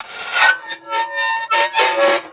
grind.wav